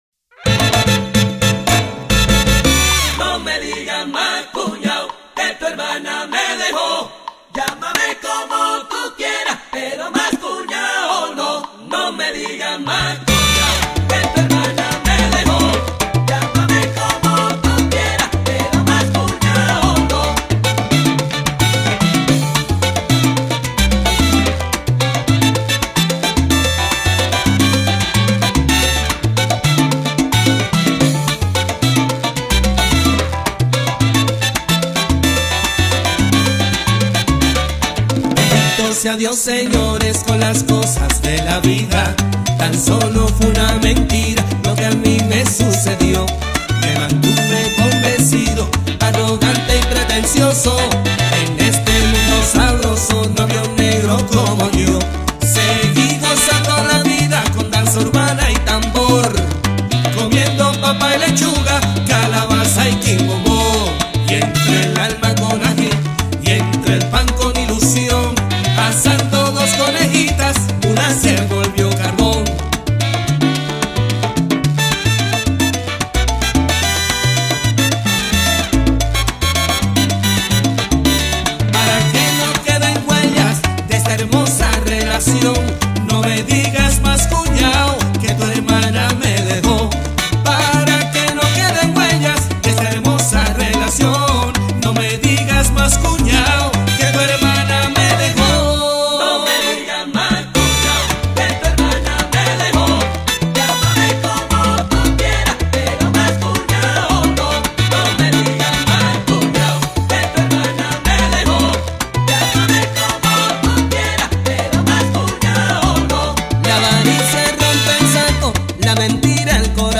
Es una pieza del folclor venezolano
sonido original de tres trompetas y dos saxofones